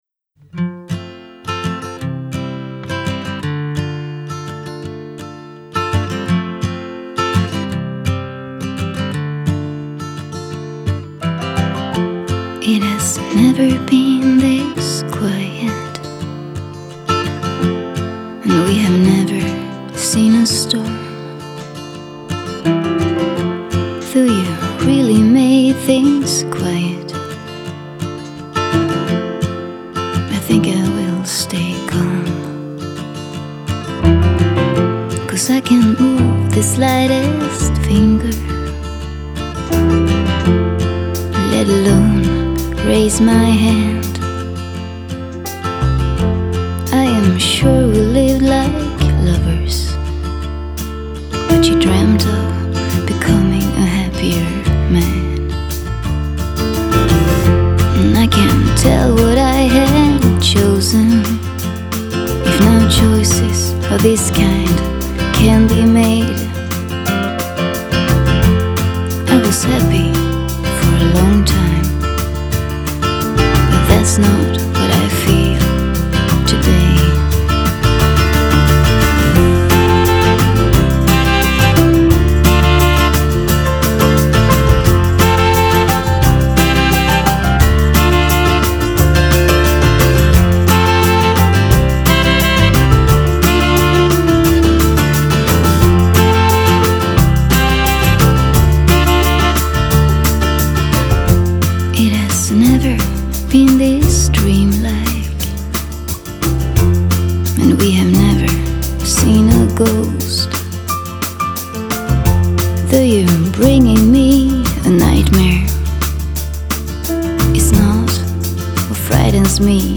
장르: Jazz, Pop
스타일: Vocal, Ballad